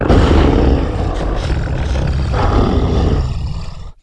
c_anusibath_dead.wav